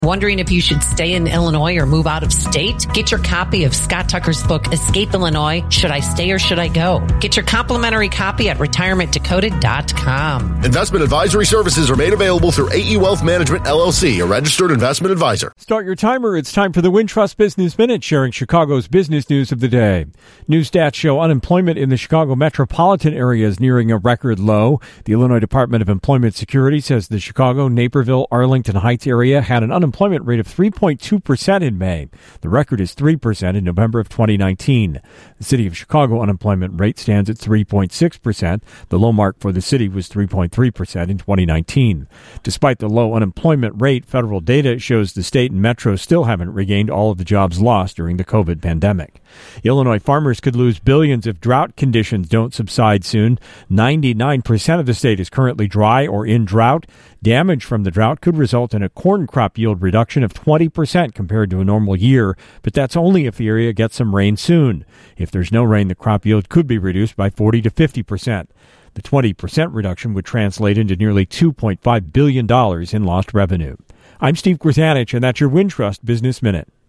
business news of the day